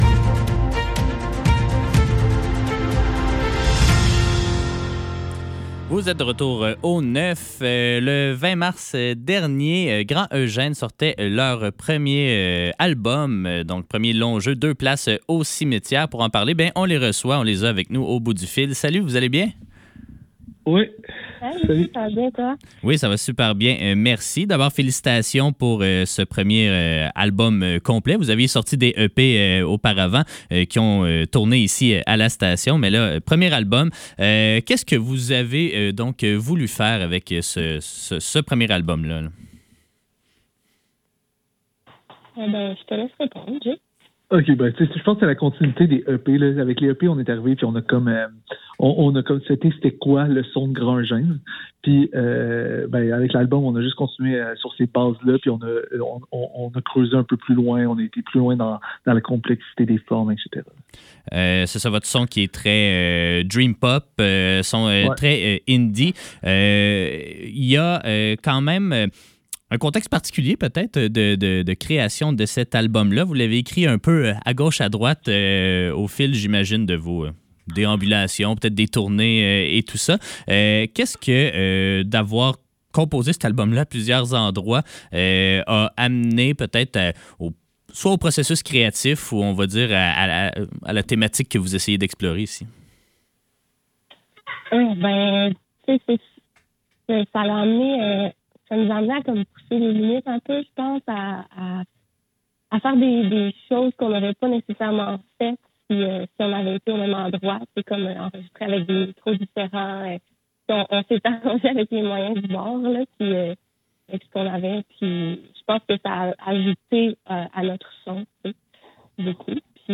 Le Neuf - Entrevue